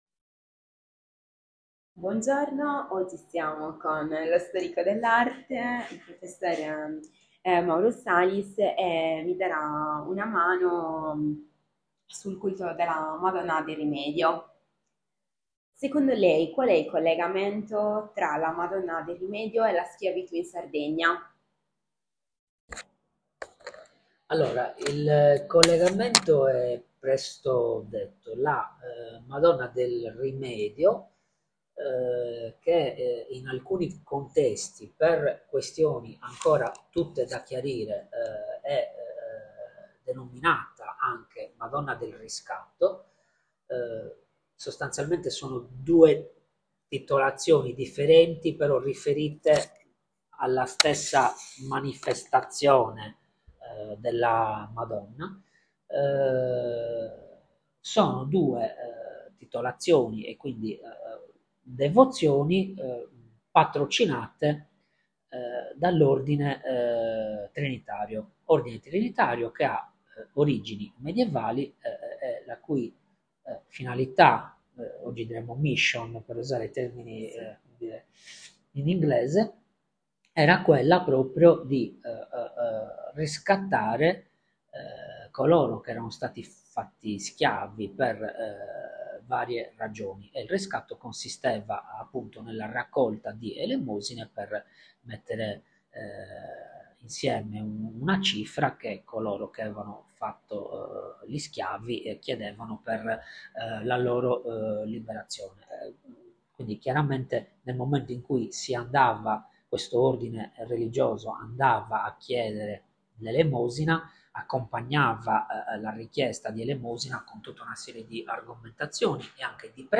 Intervista
Luogo dell'intervista Cittadella dei Musei
Apparecchiatura di registrazione Microfono e cellulare